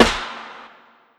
My Tears Snare.wav